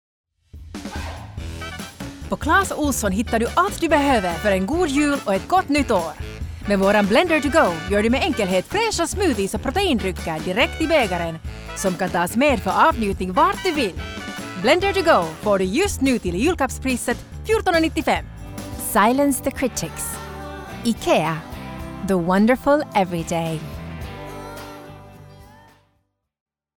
Finnish. Actress, upbeat, warm & vibrant.
Commercial Reel - Finnish & Finnish accented English